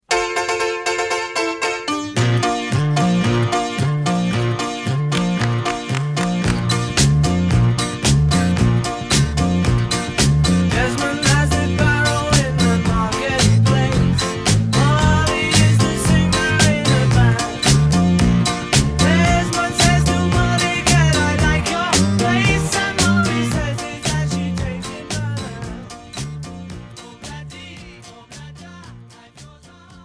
basová kytara
klavír
bicí, perkuse